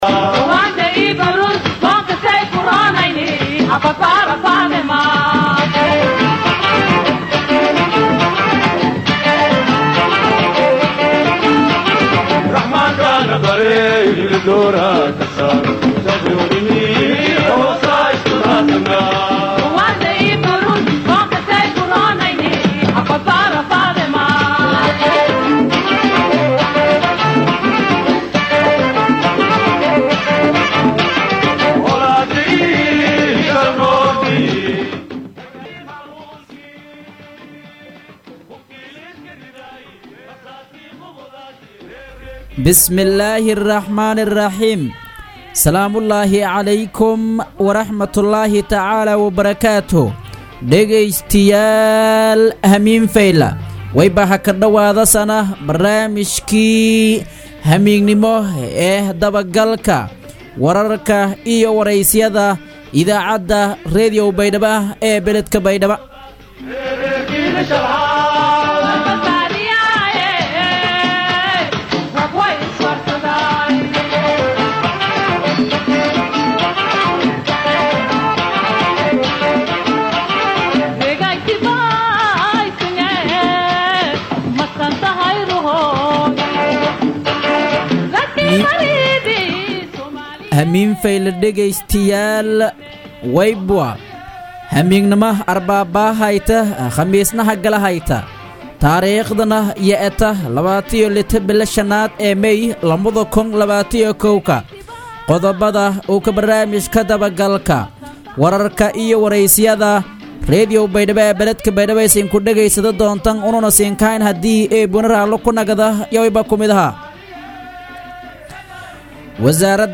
DHAGEYSO Barnaamijka Dabagalka Wararka iyo Wareysiyada Ee Radio Baidoa